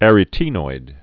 (ărĭ-tēnoid, ə-rĭtn-oid)